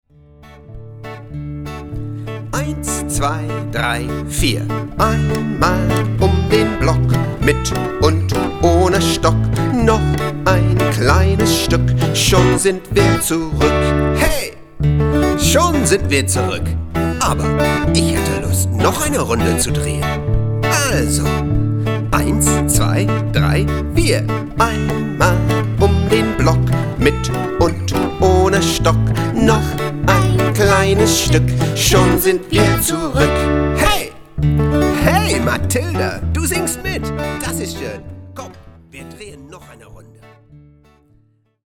Singender und spielerischer Zugang zu Frühmathe